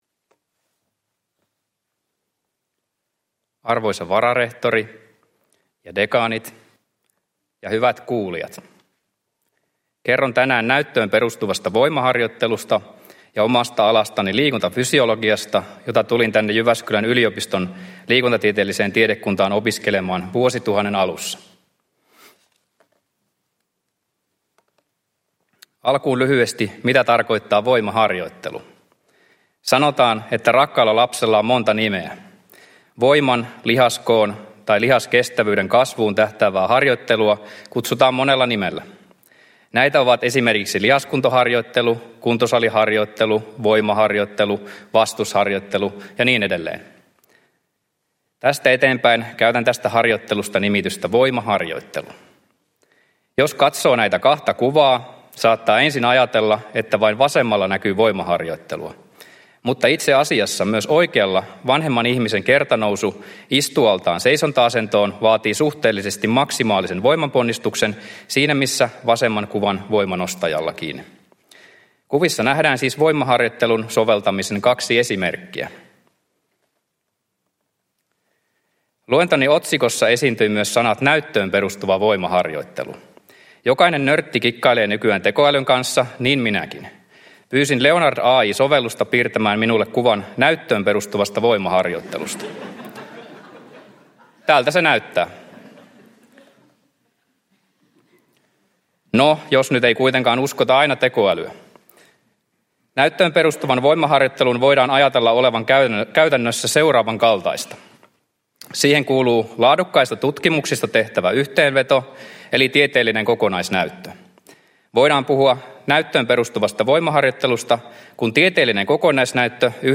Uusien professoreiden juhlaluennot 2024